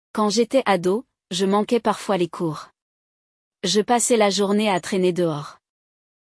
Nele, vamos ouvir uma conversa entre dois amigos que se lembram da sua época de adolescentes na escola…
LE DIALOGUE